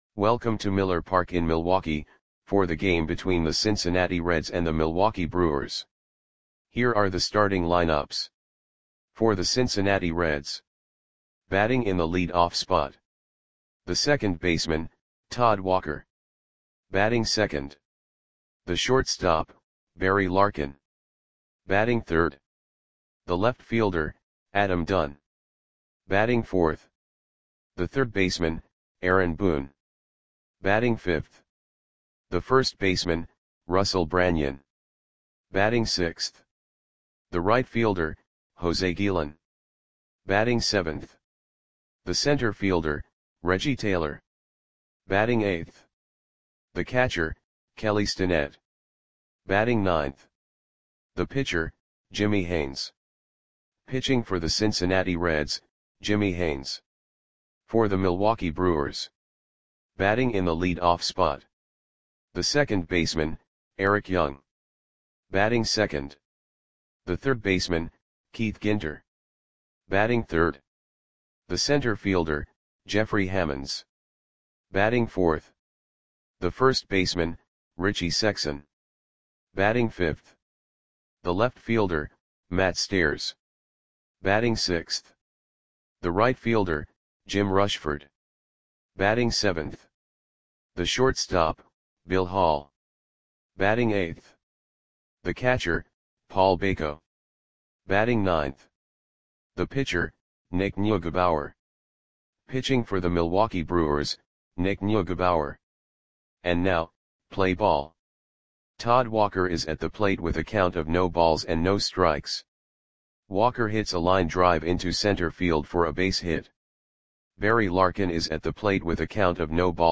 Audio Play-by-Play for Milwaukee Brewers on September 8, 2002
Click the button below to listen to the audio play-by-play.